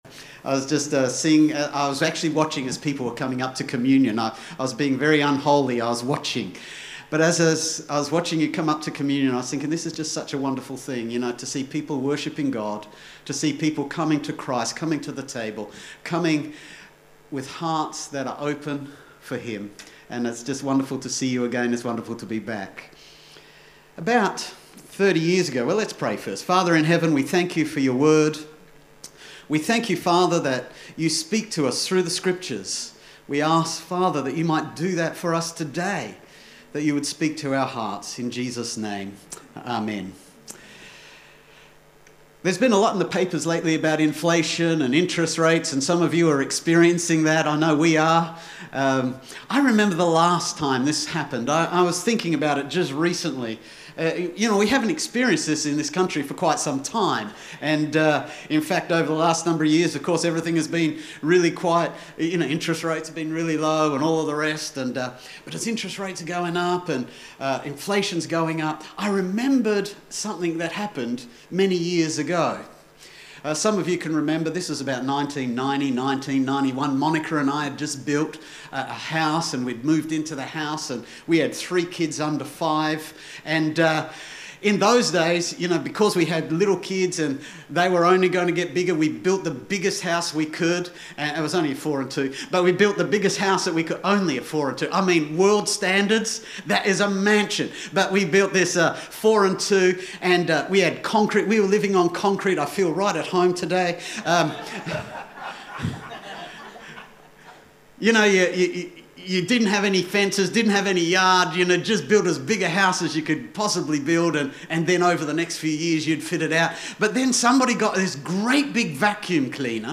A message from the series "Encourage." How do we deal when we feel anxious, distressed.